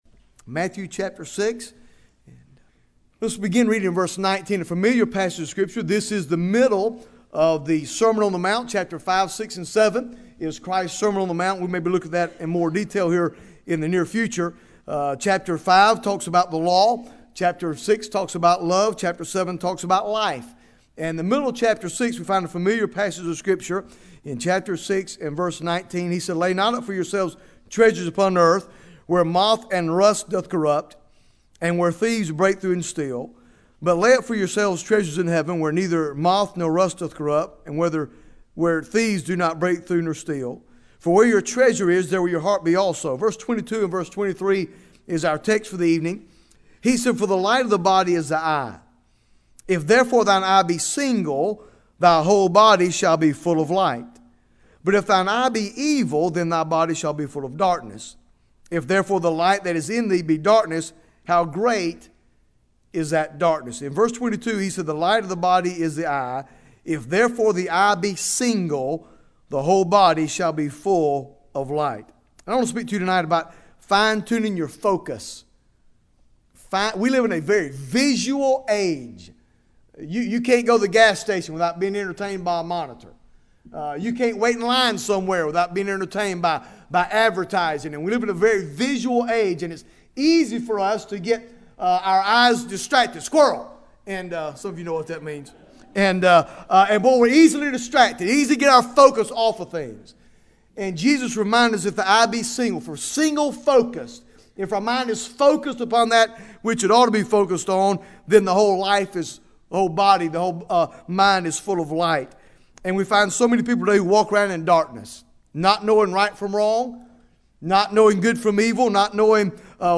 Bible Text: Matthew 6 | Preacher